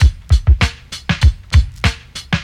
• 130 Bpm Drum Groove C# Key.wav
Free drum loop - kick tuned to the C# note. Loudest frequency: 672Hz
130-bpm-drum-groove-c-sharp-key-fj9.wav